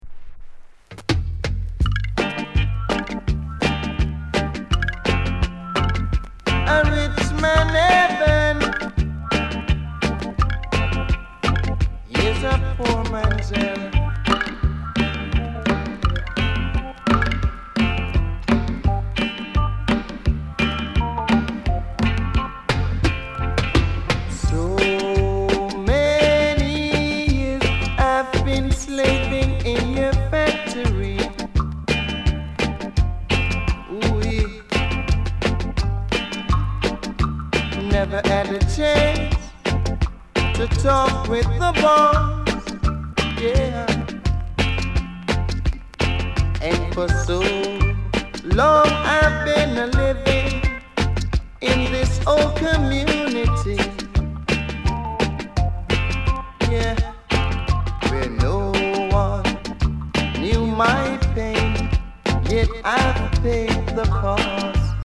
STEPPER ROOTS